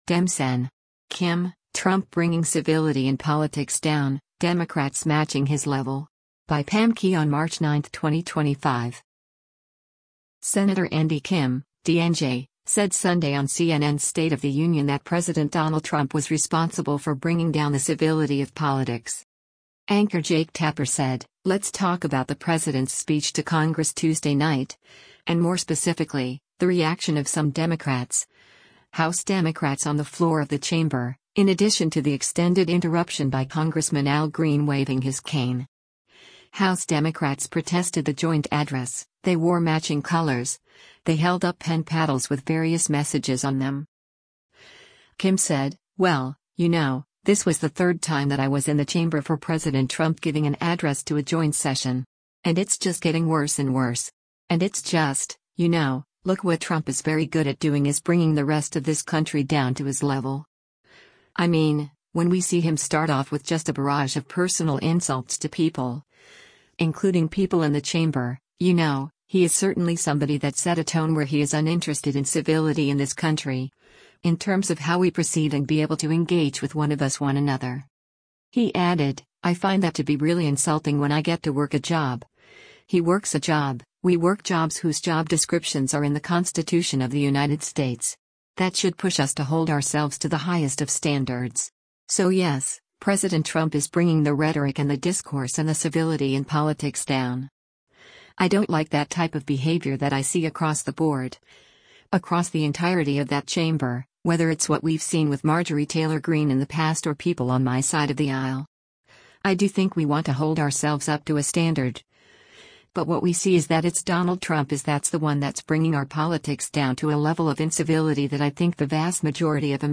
Sen. Andy Kim (D-NJ) said Sunday on CNN’s “State of the Union” that President Donald Trump was responsible for bringing down the civility of politics.